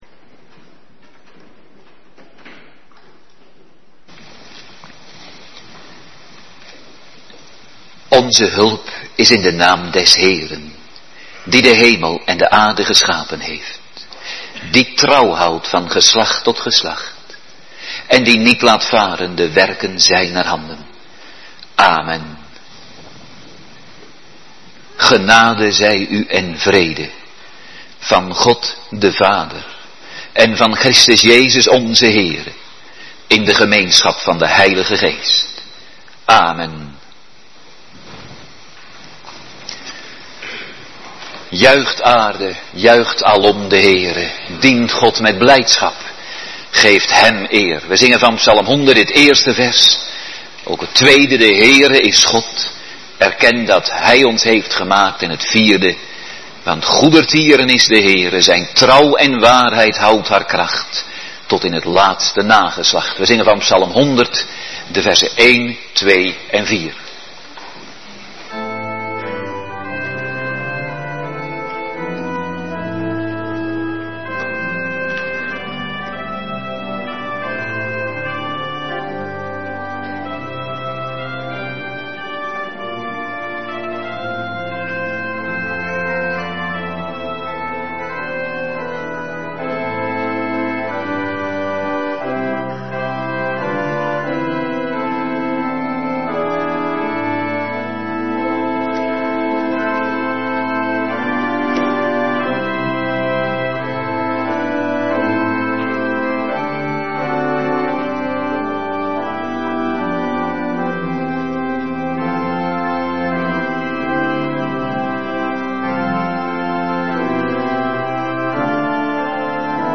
Morgendienst